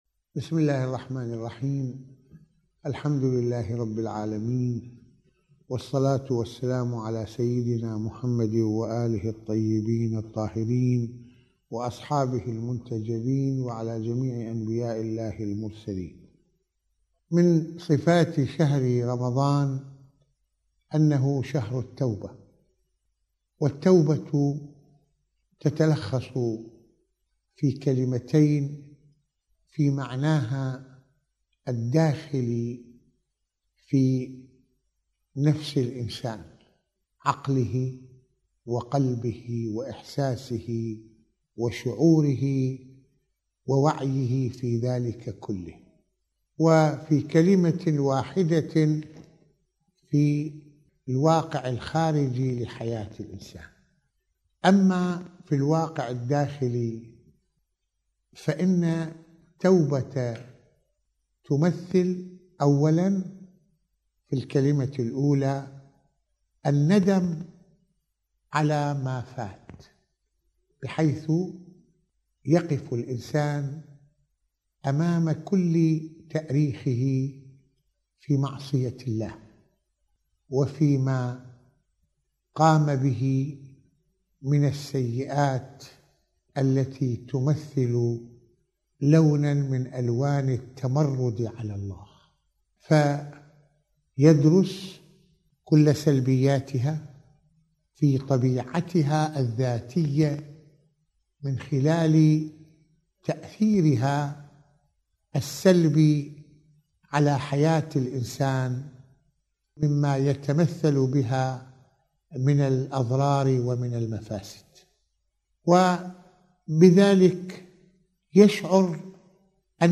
حديث السحر: التوبة | محاضرات رمضانية